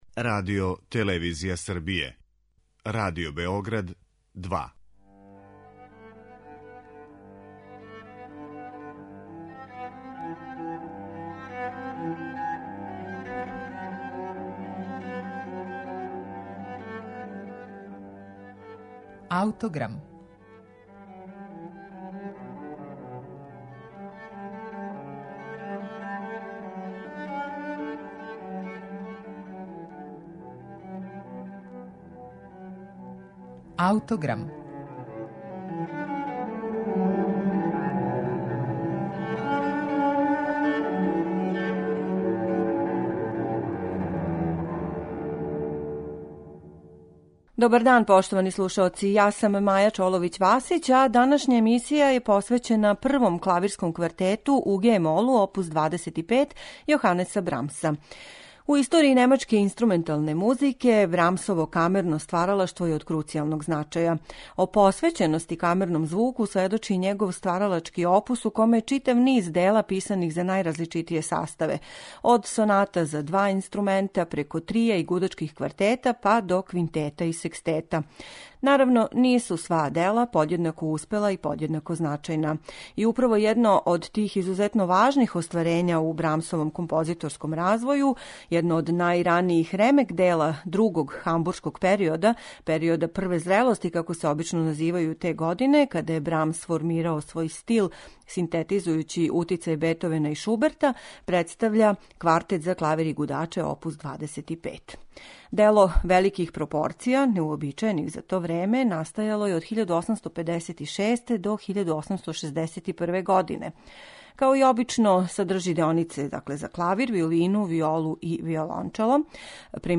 Данашња емисија је посвећена првом Клавирском квартету у гe-молу оп. 25 Јоханеса Брамса.